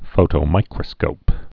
(fōtō-mīkrə-skōp)